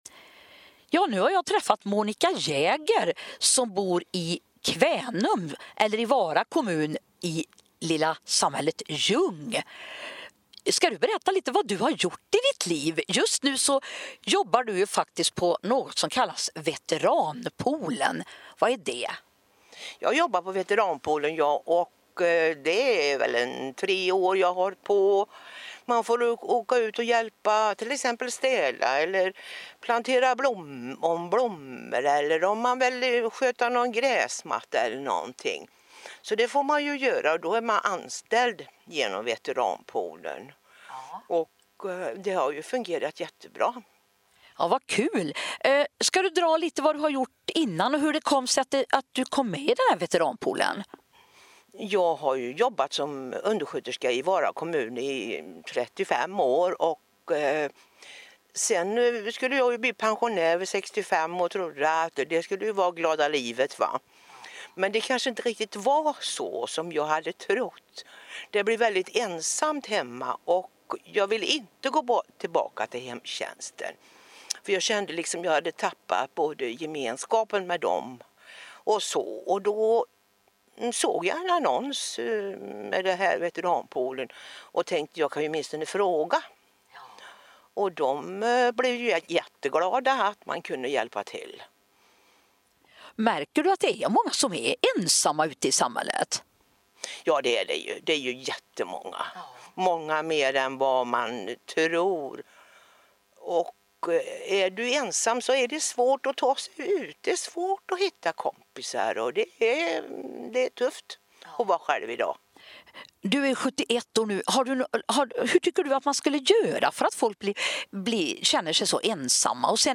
Väldigt intressant intervju.